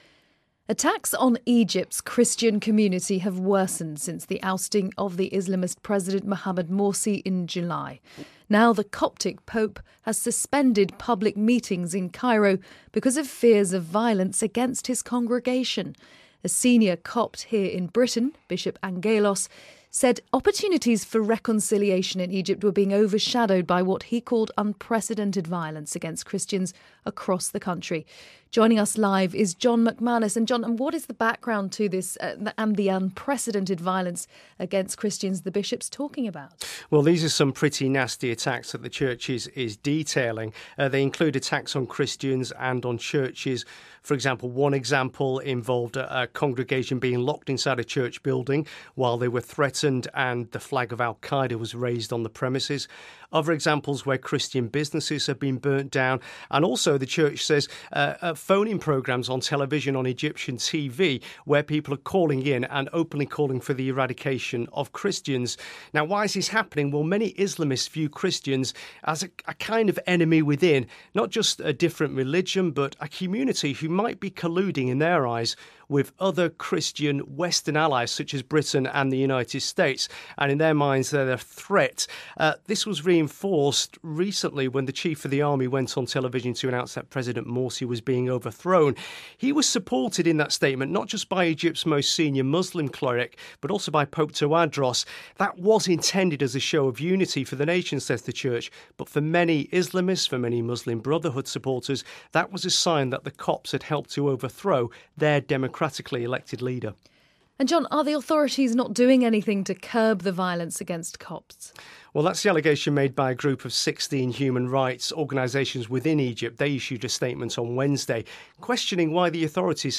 BBC News 2-way: The leader of the Coptic Church in Egypt has suspended some public meetings at St Mark's Cathedral, over concerns over possible attacks on the congregation.